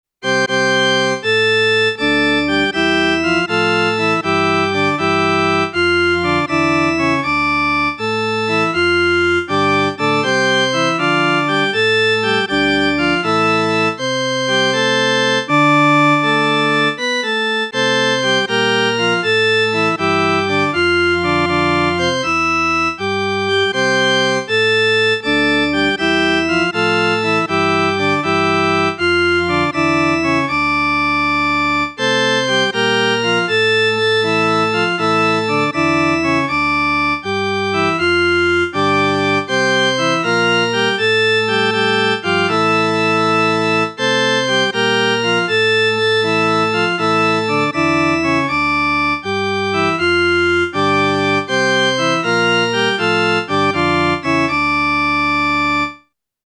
The musica ficta changes above are as follows.
Boxes 1, 2, 3 and 4: unstable minor 6th resolving to stable octave: sharpen the c’ to make a closer approach of major 6th to resolving octave.